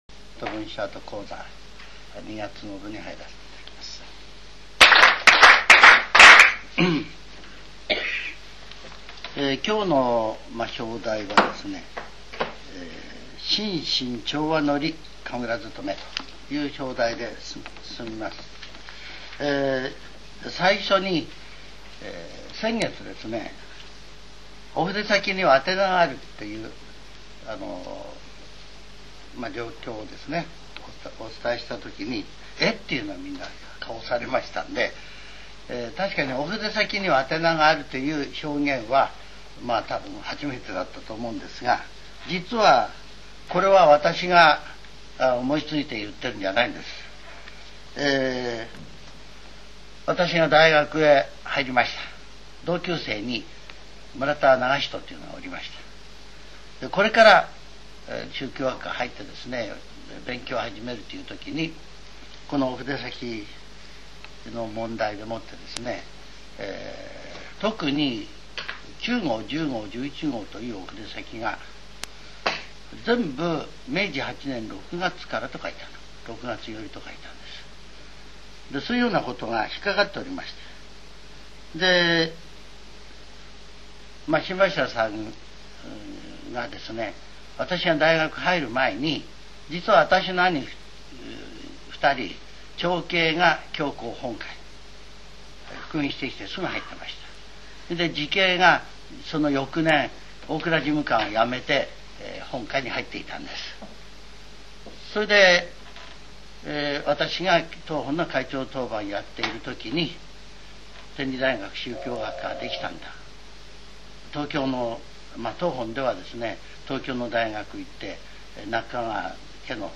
全70曲中41曲目 ジャンル: Speech